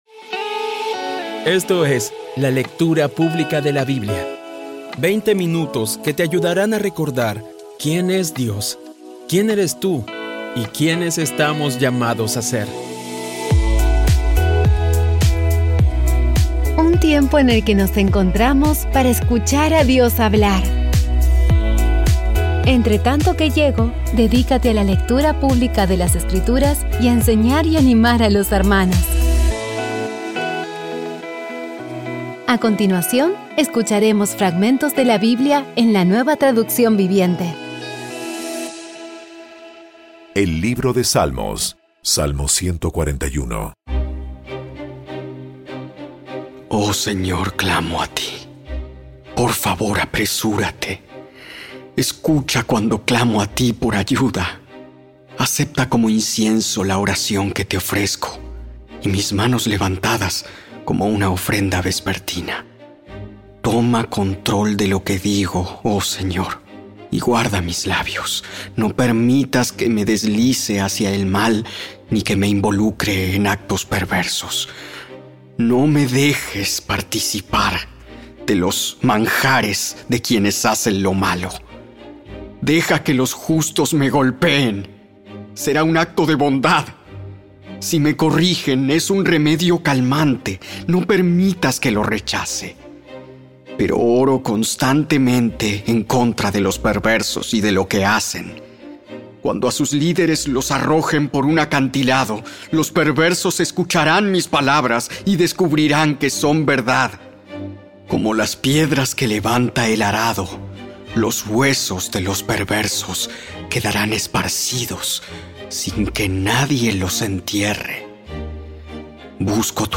Audio Biblia Dramatizada Episodio 349
Poco a poco y con las maravillosas voces actuadas de los protagonistas vas degustando las palabras de esa guía que Dios nos dio.